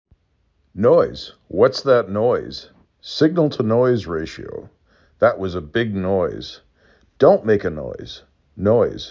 3 Phonemes
n oy z